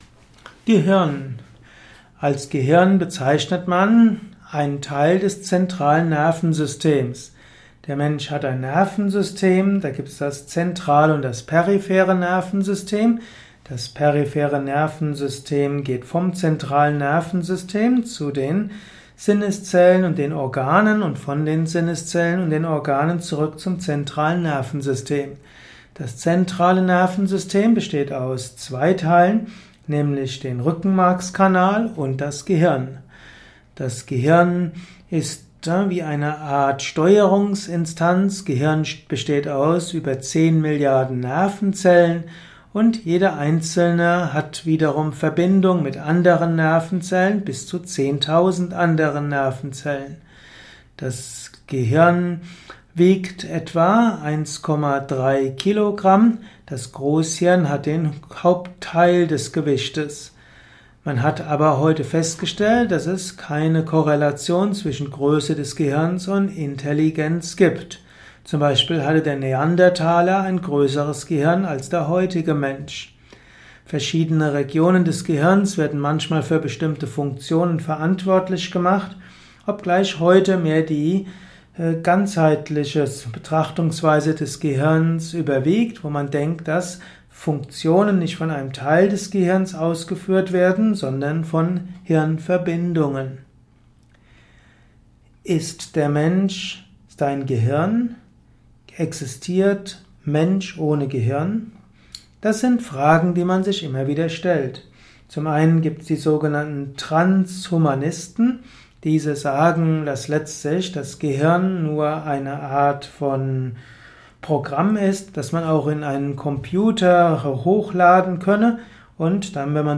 Vortragsaudio rund um das Thema Gehirn. Erfahre einiges zum Thema Gehirn in diesem kurzen Improvisations-Vortrag.